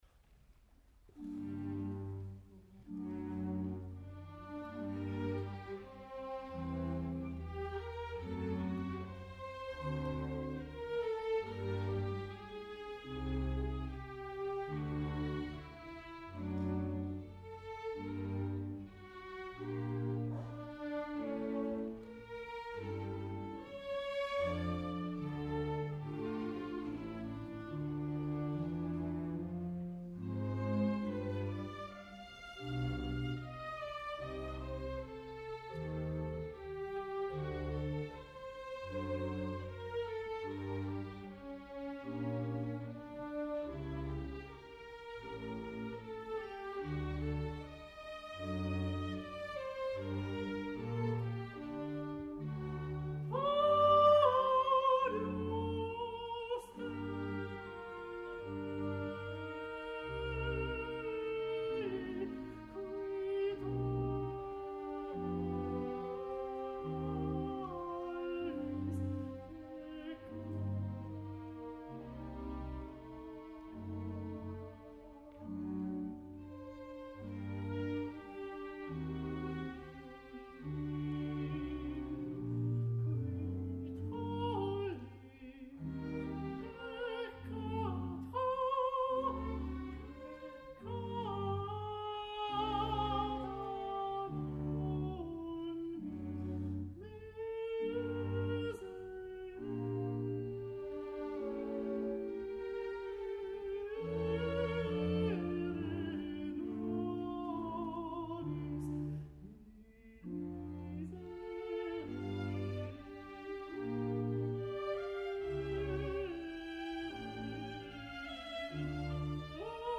２０１９年９月２８日 神奈川県立音楽堂 曲目 ミサ曲ロ短調 J.S.Bach
横浜マタイ研究会合唱団 ２０１９年９月２８日 神奈川県立音楽堂 曲目 ミサ曲ロ短調 J.S.Bach Ⅰ.